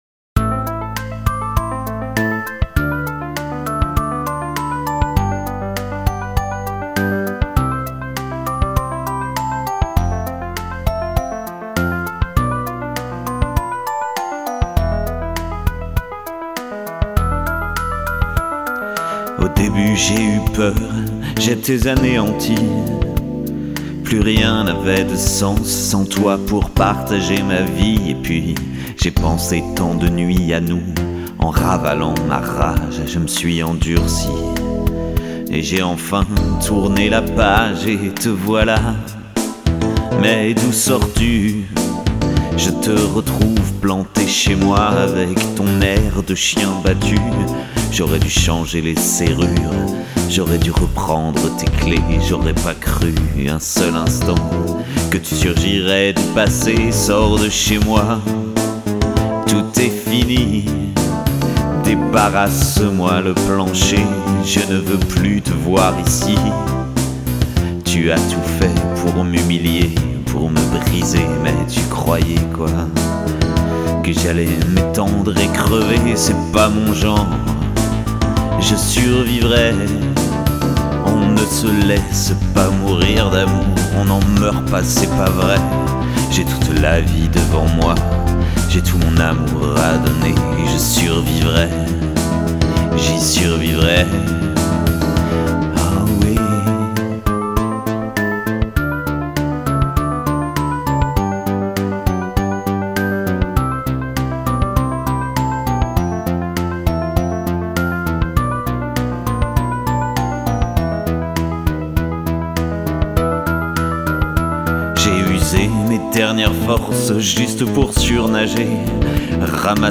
Am Andante